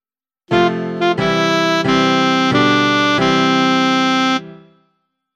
Sing “To”, and play G. Sing “You” and play C.
A quick back and forth to finish